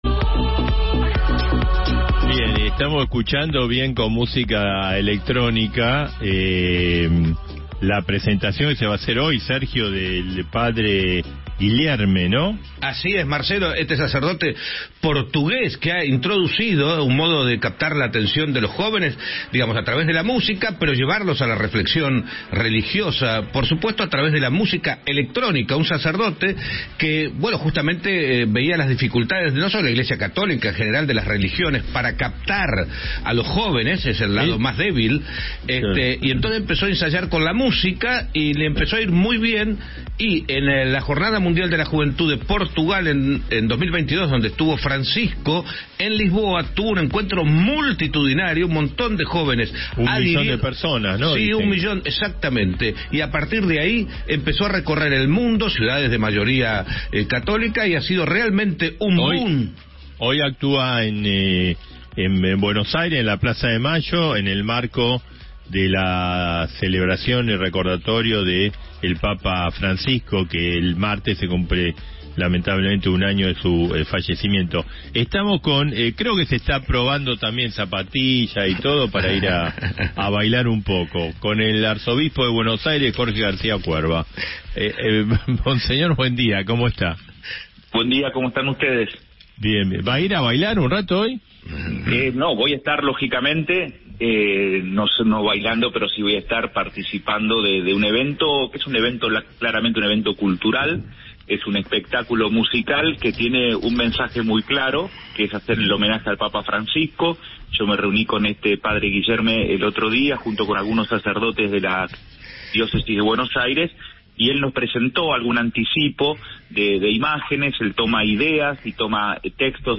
El arzobispo de Buenos Aires, Mons. Jorge García Cuerva, brindó una entrevista radial en la previa al homenaje al papa Francisco.